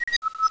Hail.snd